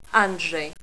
Here you have an example how to pronounce my name in Polish.